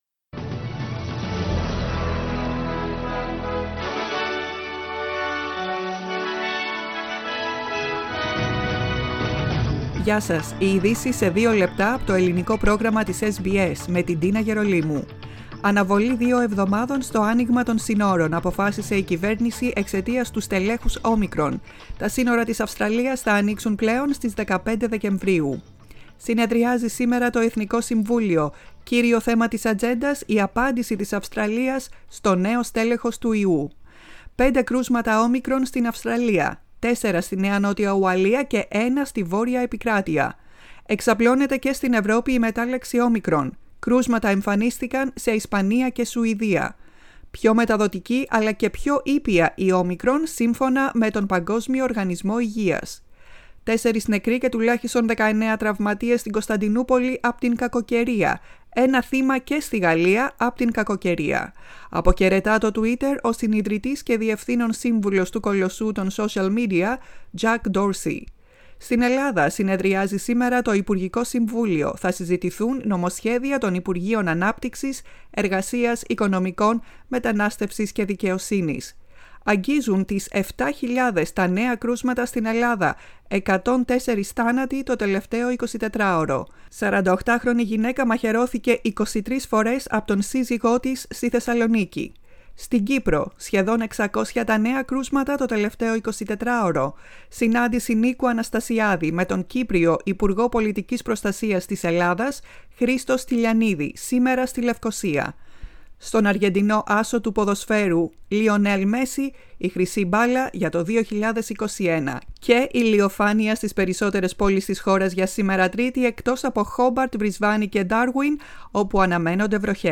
Σύντομο δελτίο ειδήσεων με τις κυριότερες ειδήσεις της ημέρας απ΄το Ελληνικό Πρόγραμμα της SBS
News Flash in Greek.